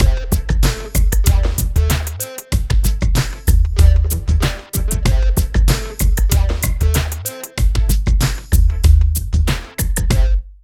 62 LOOP   -L.wav